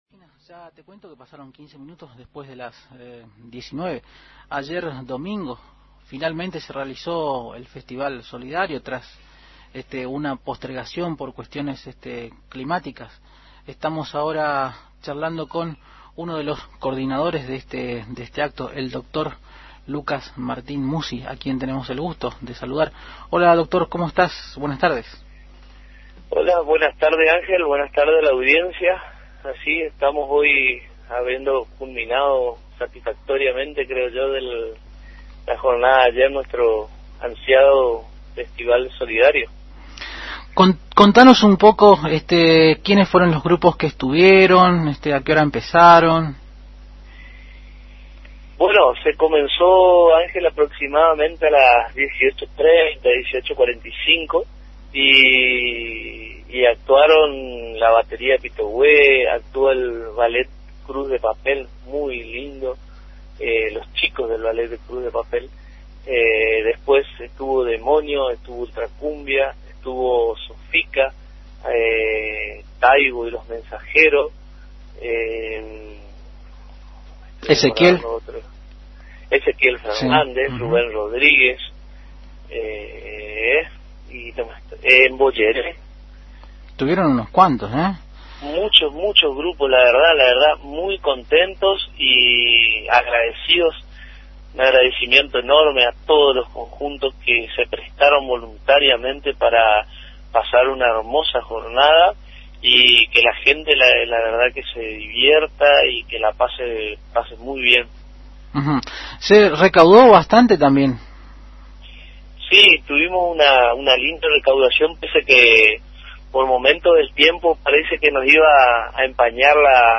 En contacto con Agenda 970 el coordinador manifestó que en el transcurso de esta semana van a estar clasificando las mercaderías obtenidas y luego estarán repartiendo en viviendas necesitadas de la zona sur de la ciudad.